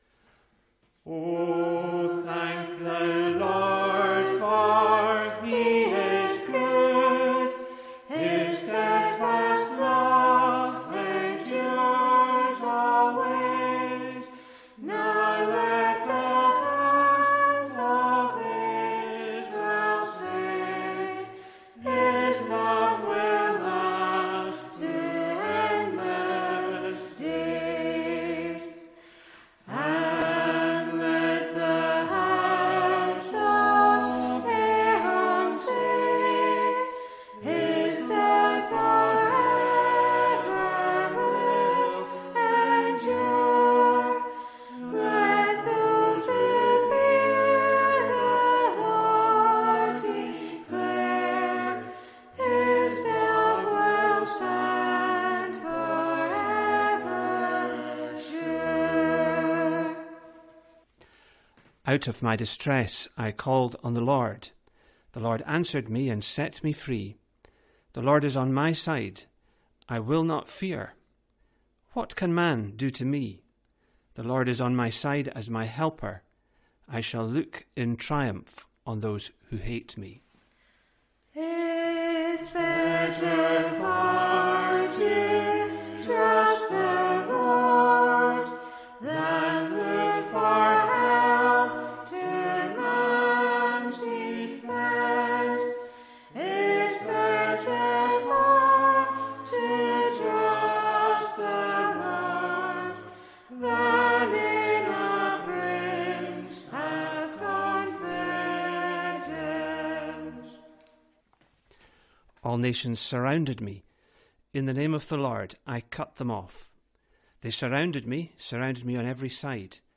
Congregational Psalm Singing
Psalm 118 (Song 34) Sung & Read.
Recorded during the first lockdown when church services went on-line, with five of us singing live at a microphone built for one.